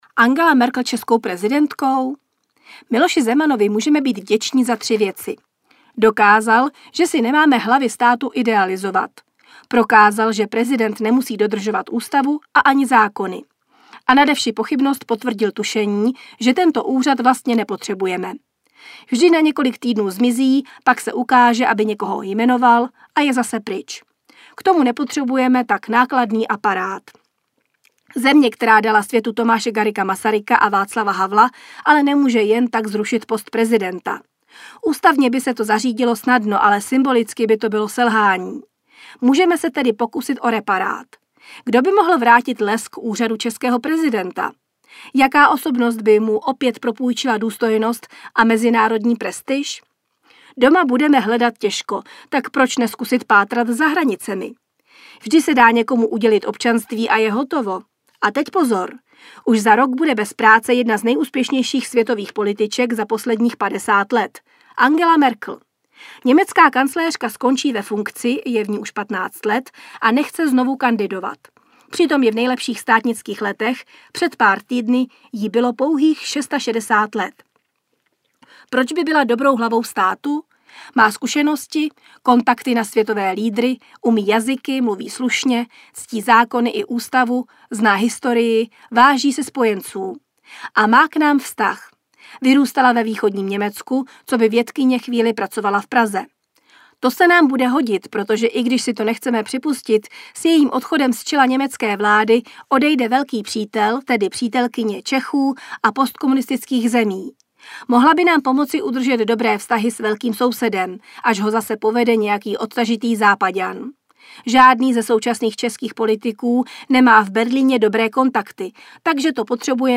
Nahráno ve společnosti 5Guests.
Respekt 8/2023– Audiotéka ve spolupráci s českým týdeníkem Respekt představuje Respekt v audioverzi.